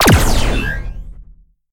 .开火5.ogg